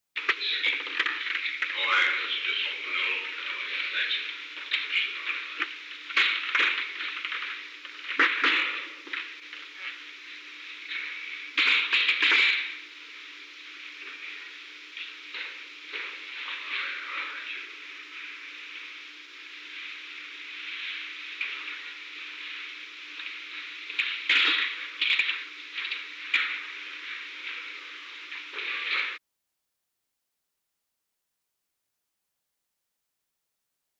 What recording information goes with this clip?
Recording Device: Oval Office